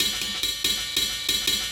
Ride 07.wav